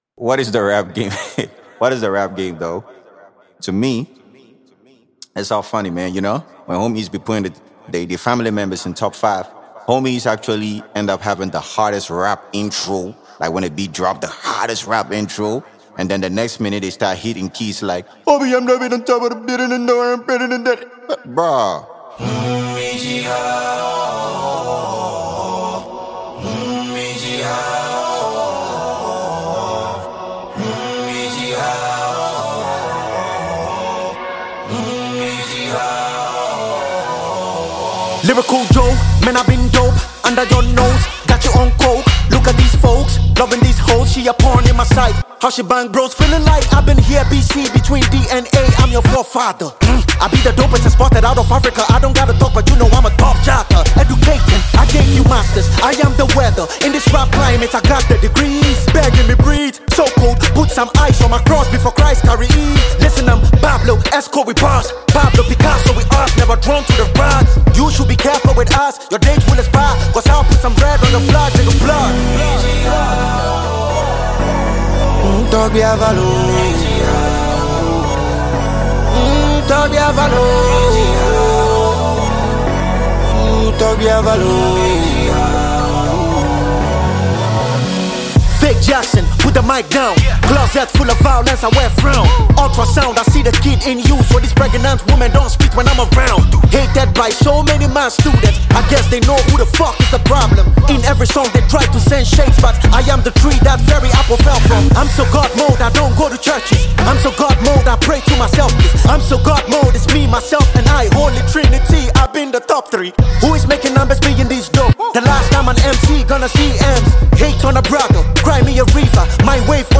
Award-winning Ghanaian tongue-twisting musician